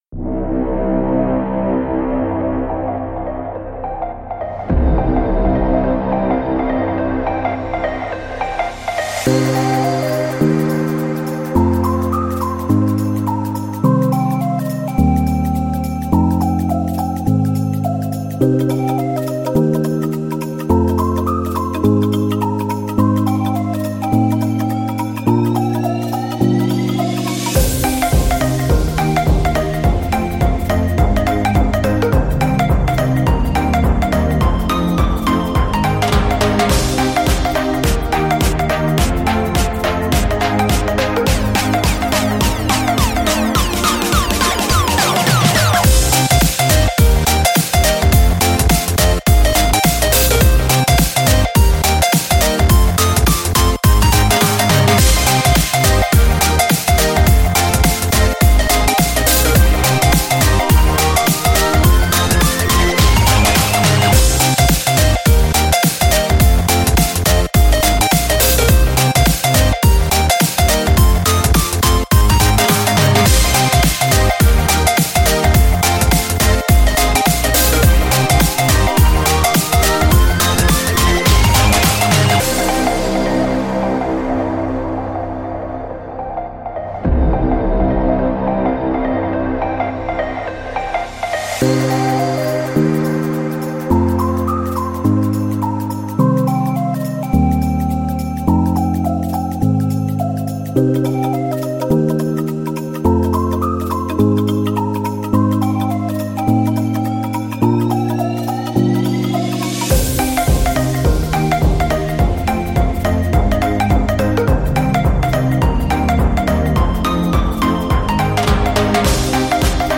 EDM
37 LOOPS
118 ONE SHOTS